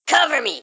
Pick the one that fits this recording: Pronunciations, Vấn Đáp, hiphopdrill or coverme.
coverme